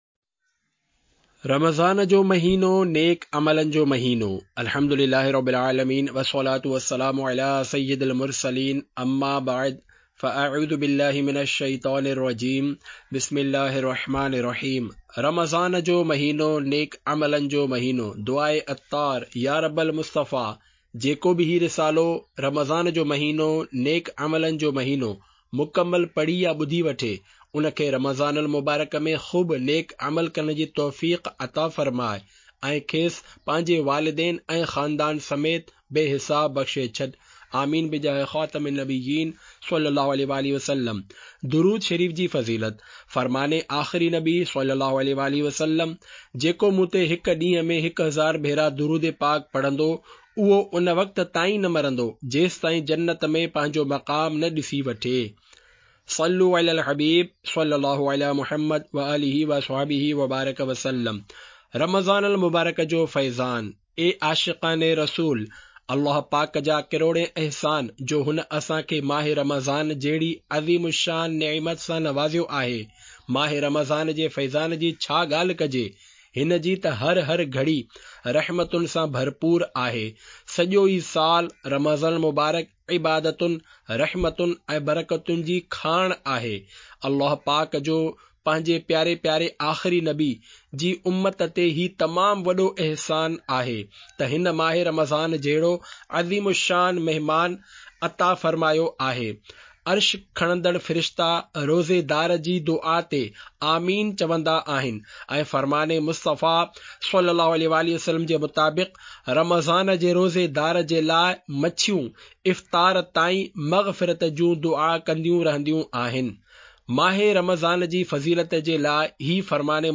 Audiobook - Mah e Ramzan Mah e Naik Aamal (Sindhi)